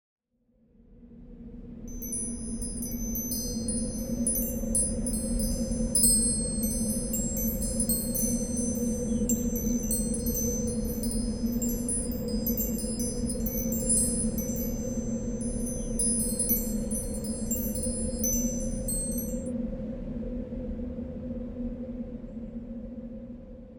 windchime.ogg